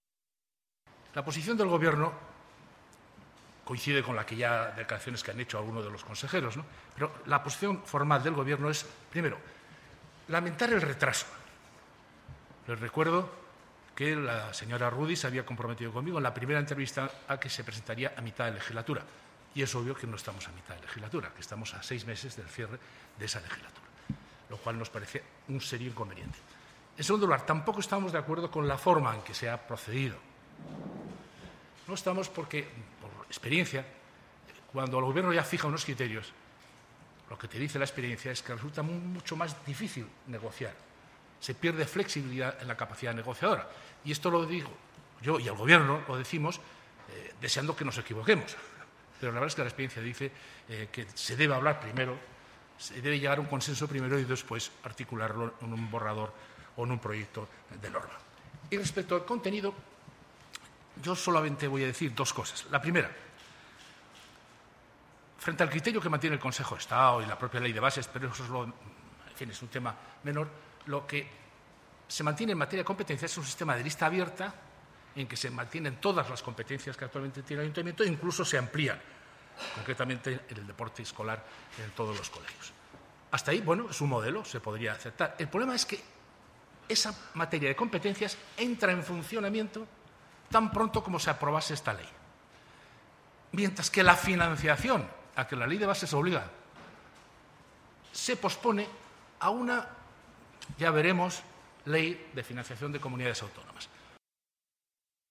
Concretamente, Juan Alberto Belloch ha dicho: